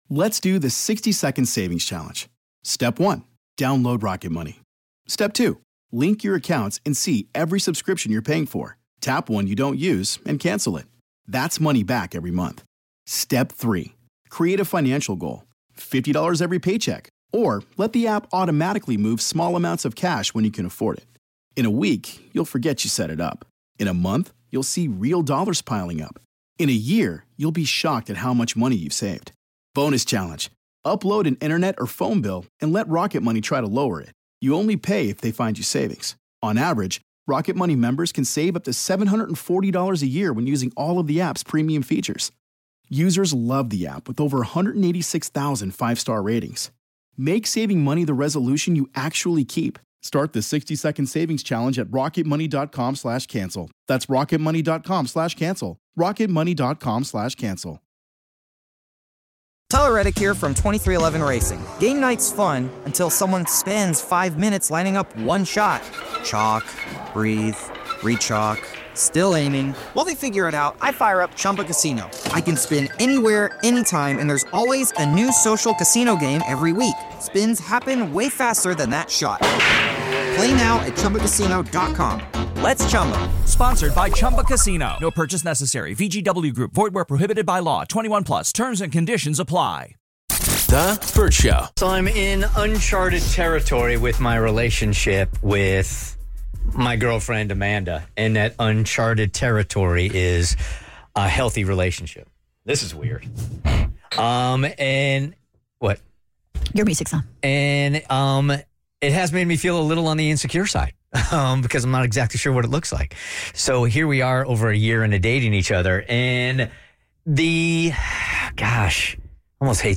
The Bert Show took a relationship style quiz live on air to find out how codependent they are on their partners!